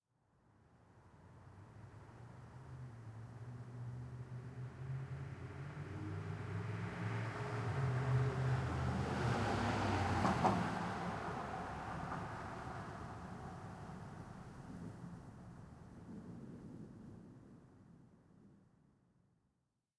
1Shot Vehicle Passby with Tire Bumps ST450 07_ambiX.wav